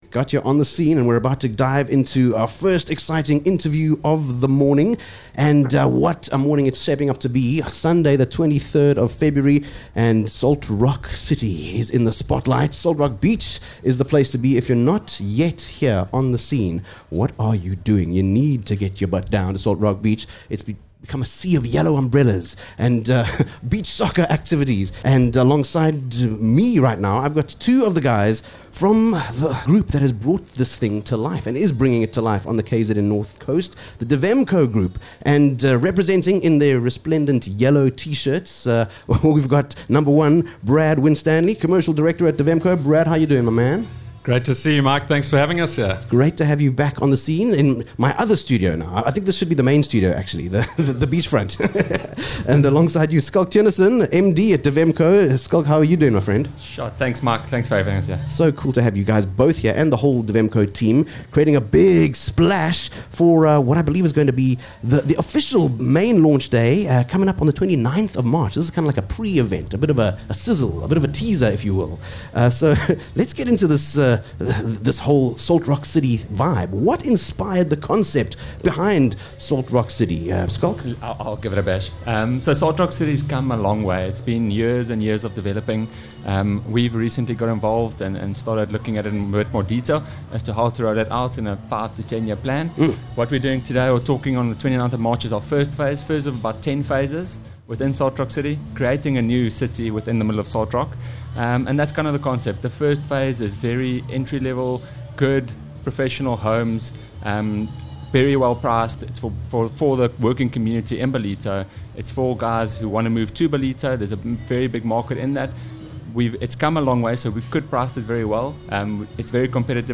23 Feb Live from Salt Rock Main Beach: The Salt Rock City Family Beach Day